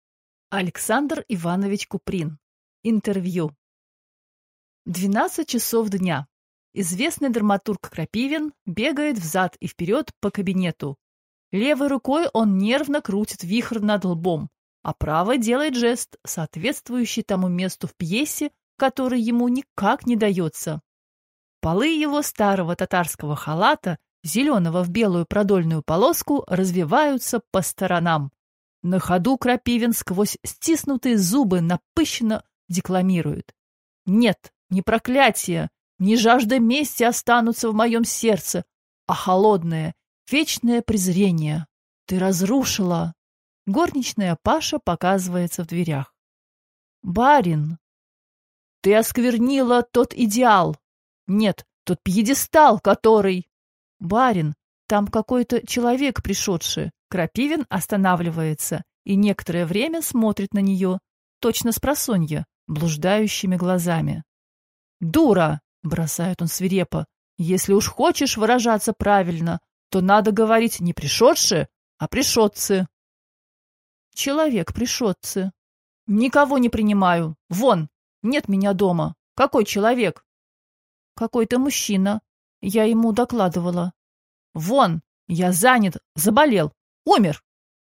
Аудиокнига Интервью | Библиотека аудиокниг